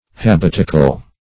Search Result for " habitacle" : The Collaborative International Dictionary of English v.0.48: Habitacle \Hab"it*a*cle\ (h[a^]b"[i^]t*[.a]*k'l), n. [F. habitacle dwelling place, binnacle, L. habitaculum dwelling place.